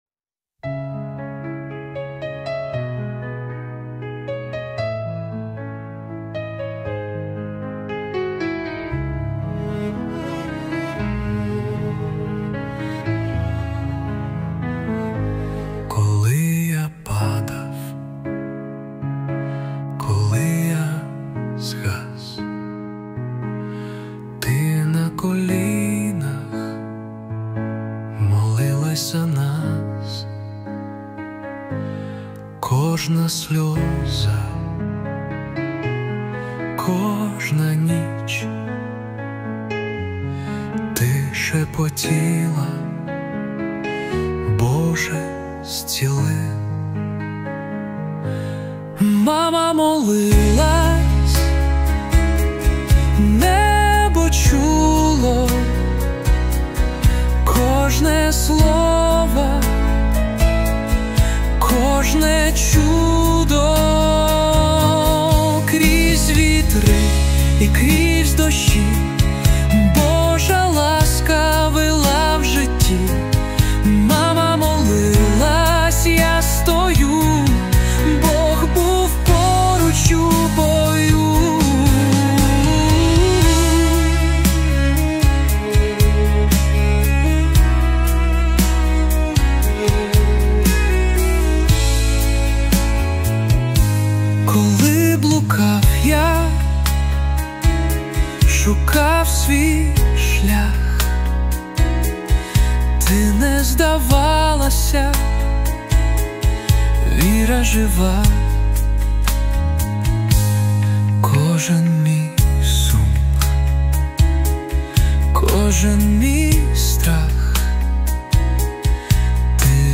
песня ai
331 просмотр 332 прослушивания 30 скачиваний BPM: 60